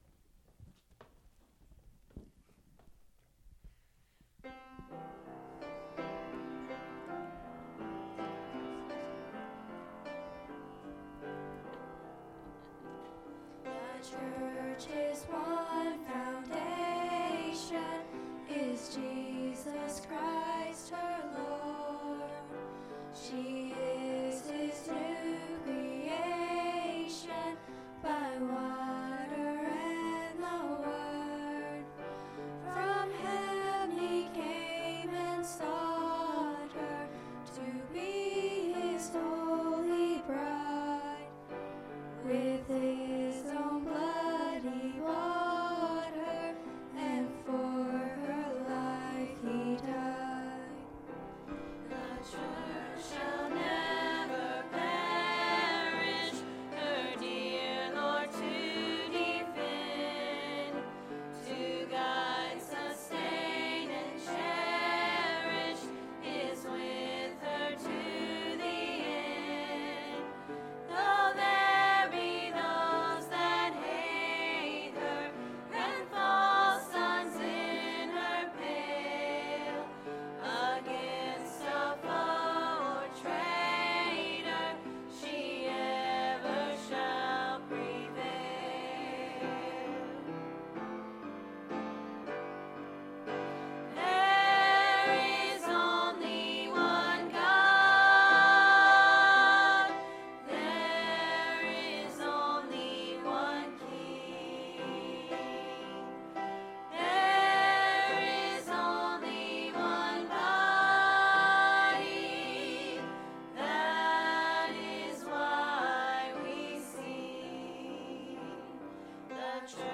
Sermons | Shady Grove Church